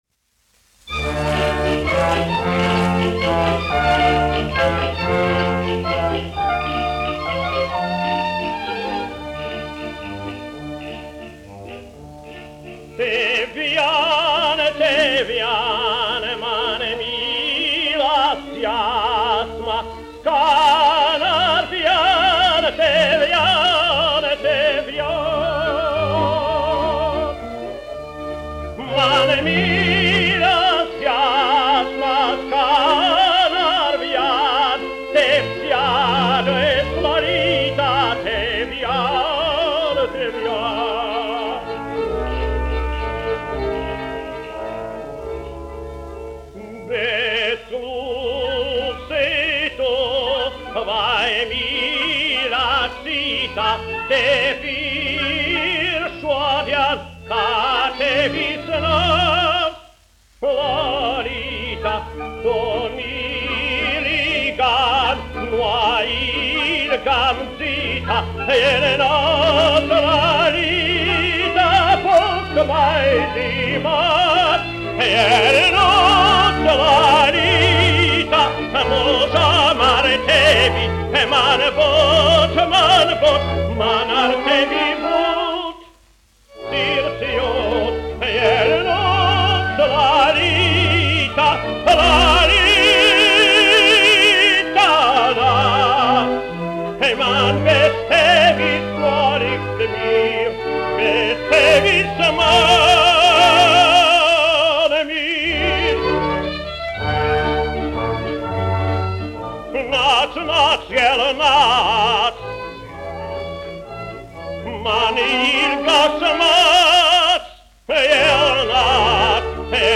1 skpl. : analogs, 78 apgr/min, mono ; 25 cm
Dziesmas (augsta balss) ar orķestri
Skaņuplate
Latvijas vēsturiskie šellaka skaņuplašu ieraksti (Kolekcija)